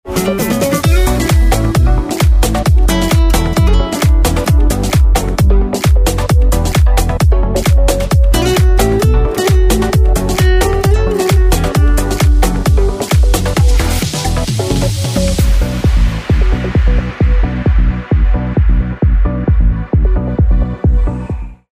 زنگ موبایل
رینگتون با انرژی و بیکلام